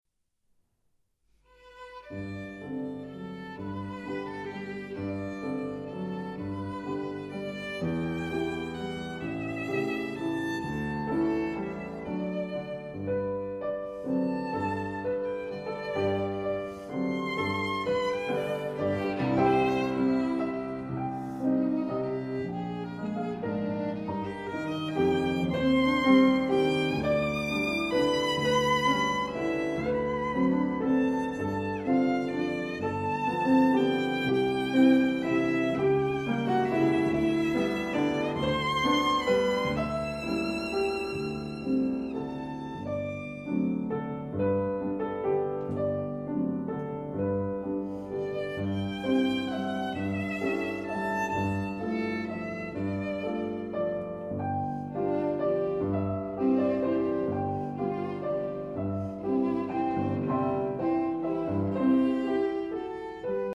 Musique romantique
2) Andantino. Allegretto, un poco vivace. Tempo primo